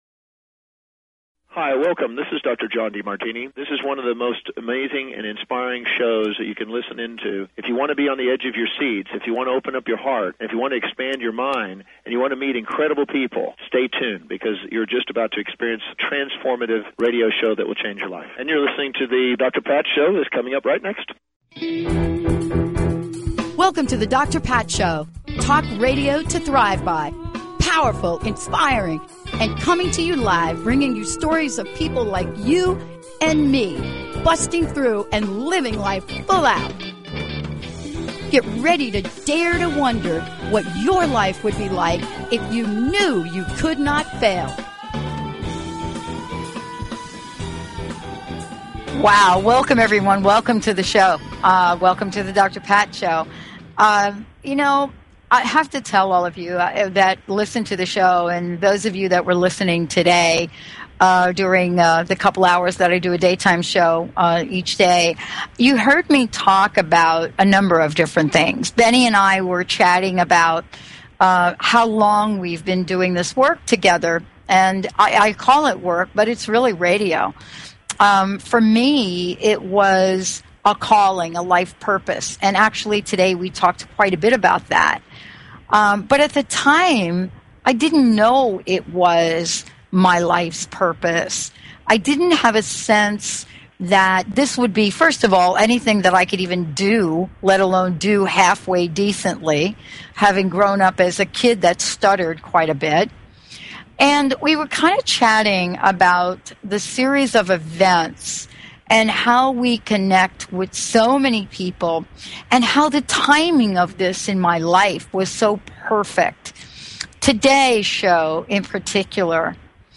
Talk Show Episode, Audio Podcast